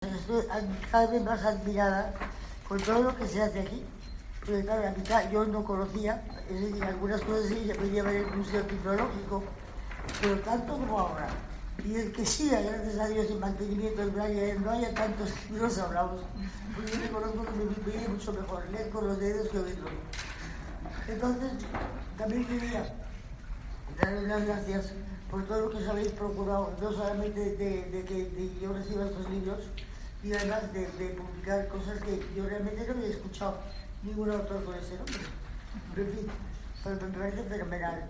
expresó luego la infanta en una breve intervención formato MP3 audio(1,45 MB), quedó "admirada" de la ingente labor de edición que se realiza en ese centro y especialmente agradecida de que se mantenga la producción de texto en braille, que reconoció preferir a otros posibles formatos.